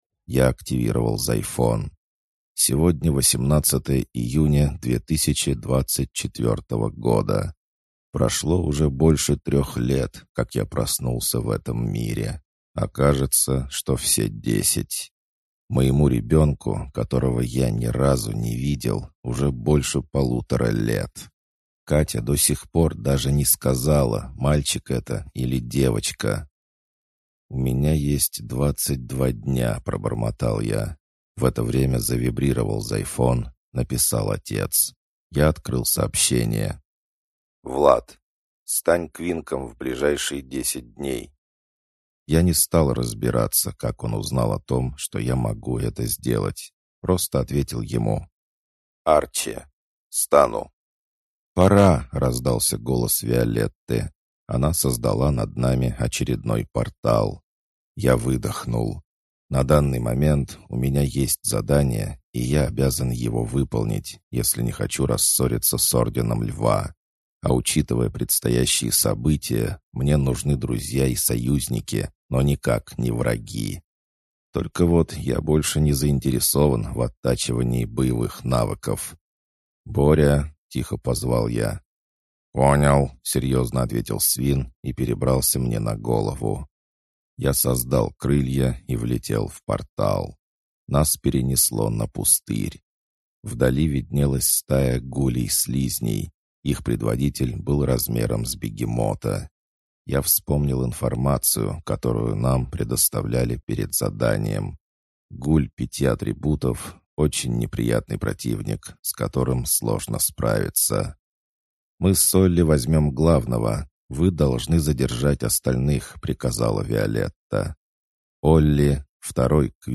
Аудиокнига Кровавый Трон | Библиотека аудиокниг